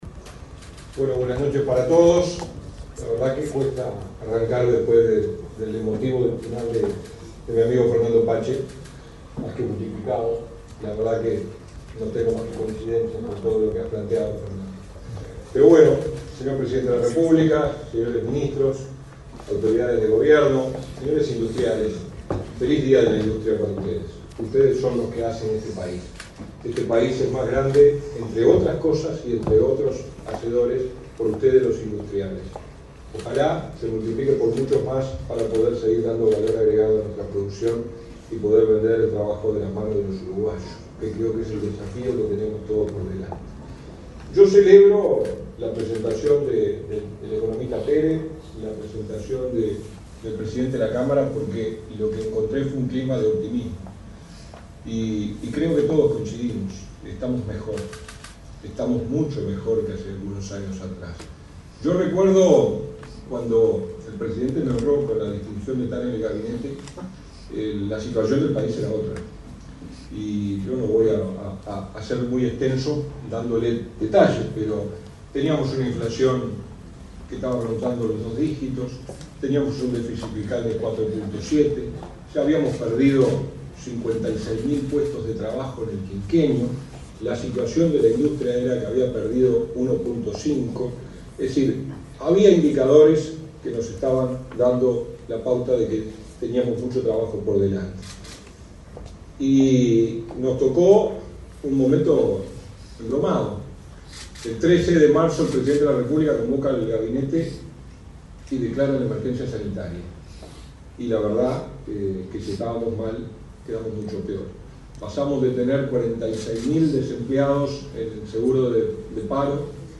Palabras del ministro interino de Industria, Walter Verri
El ministro interino de Industria, Walter Verri, se expresó, este jueves 14 en Montevideo, durante la celebración del Día de la Industria y el 126.°